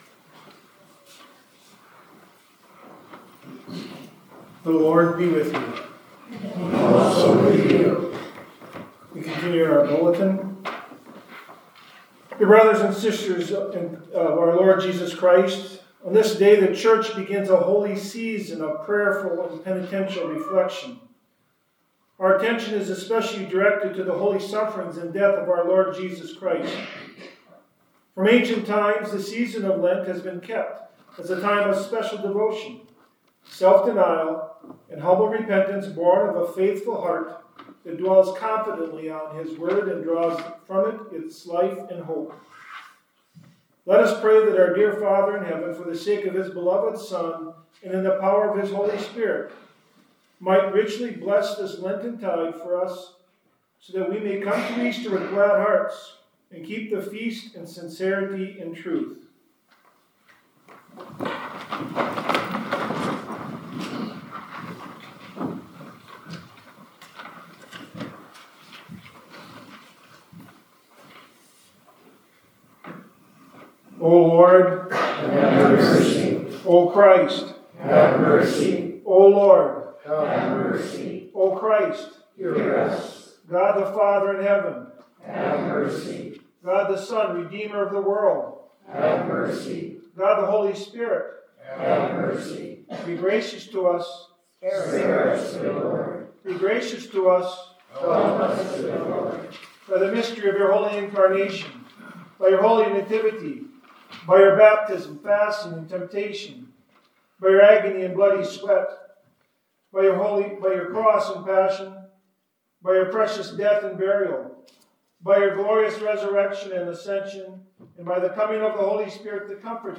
Immanuel Worship March 5th, 2025 (Ash Wednesday)